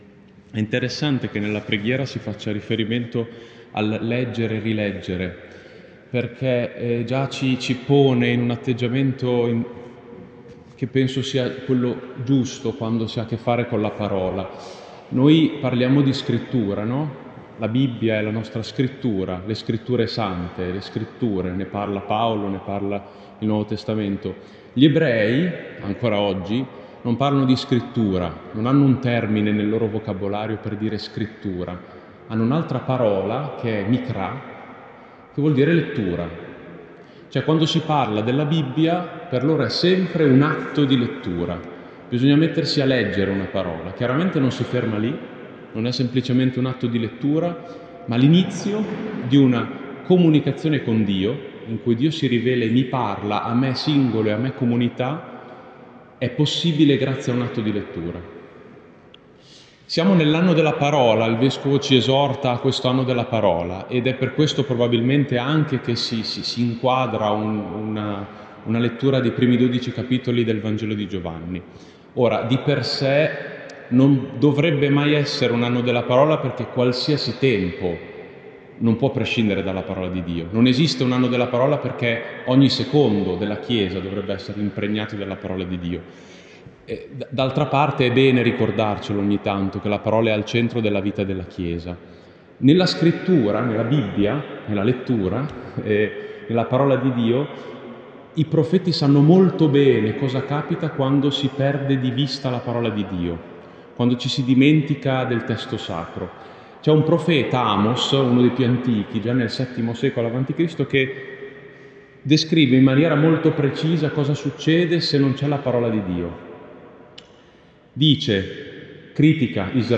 Di seguito gli audio degli incontri tenuti nella Zona Pastorale.